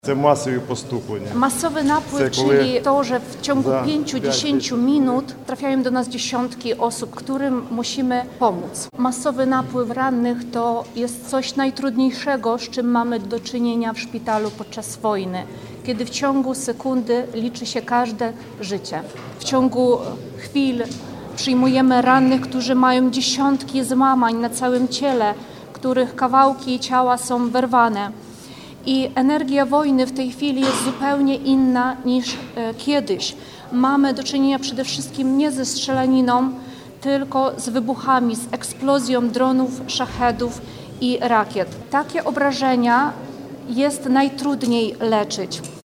O tym rozmawiali eksperci podczas konferencji na Politechnice Wrocławskiej pod hasłem: „Bezpieczeństwo i funkcjonowanie podmiotów leczniczych w sytuacjach kryzysowych na podstawie doświadczeń Ukrainy. Zabezpieczenie medyczne wojsk na potrzeby obronne w Polsce”.